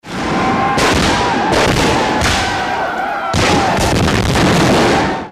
nri-battlesounds.mp3